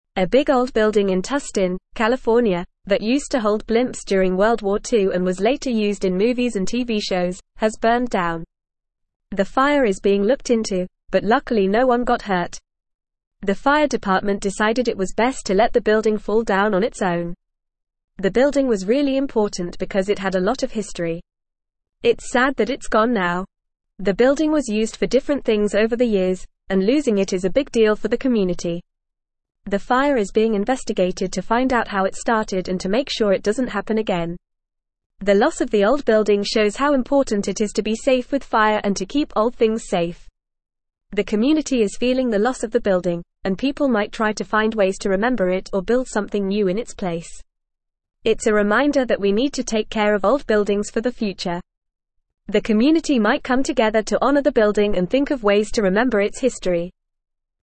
Fast
English-Newsroom-Upper-Intermediate-FAST-Reading-Historic-World-War-Two-Era-Blimp-Hangar-Engulfed-in-Flames.mp3